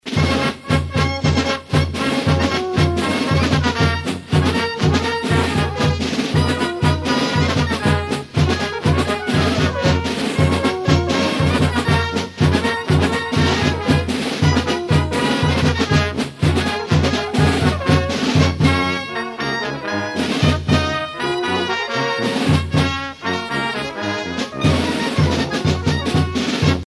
Polka marche
carnaval, mardi-gras